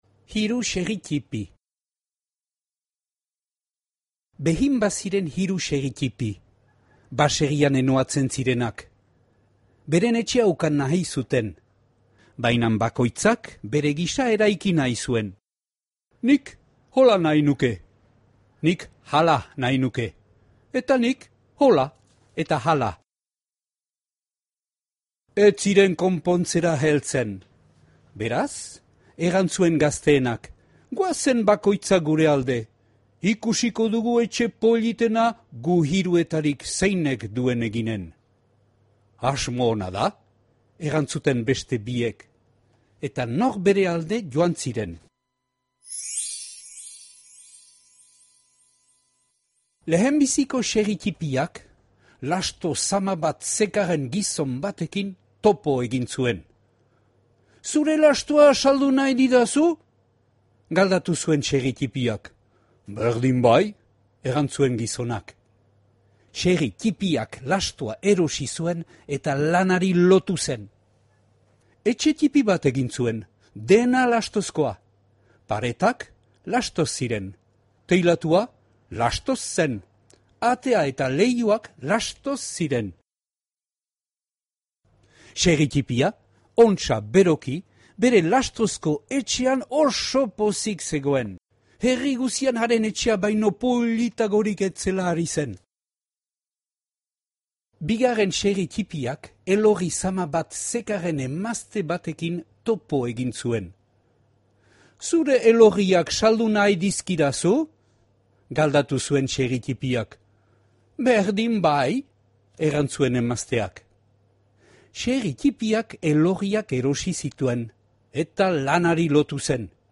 Hiru xerri ttipi - Batuaz - ipuina entzungai
Flammarion Père Castor saileko Les trois petits cochons albumaren itzulpena, CD batean grabatua.